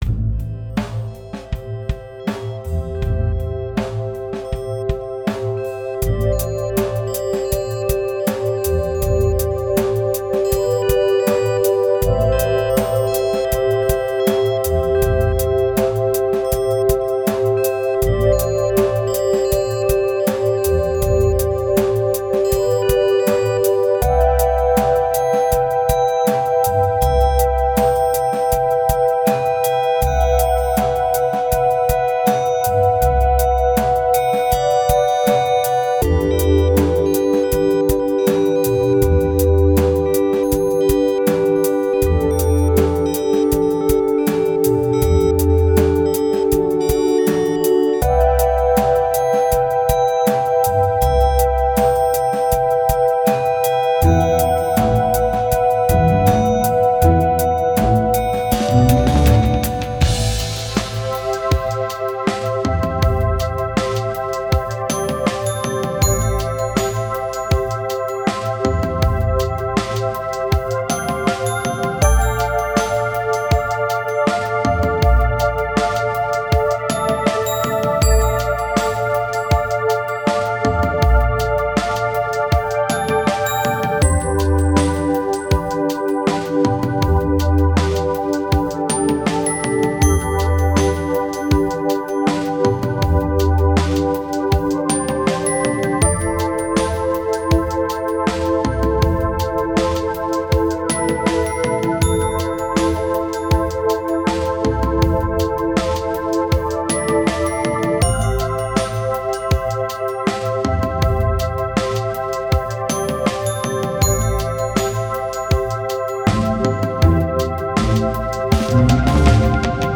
ορχηστρικές συνθέσεις
Lounge & Calm διάθεση